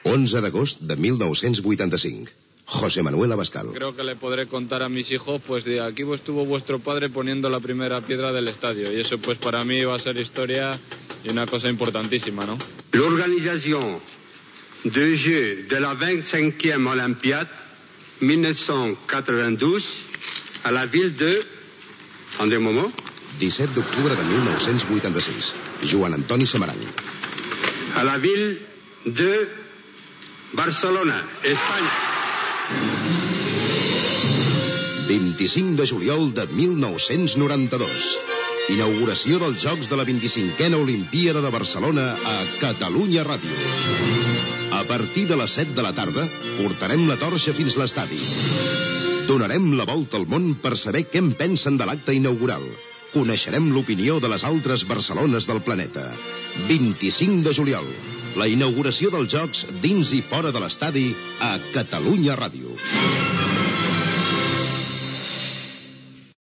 Promoció de la programació especial del 27 de juliol de l'any 1992 amb motiu de la inaguració dels Jocs Olímpics de Barcelona 92
FM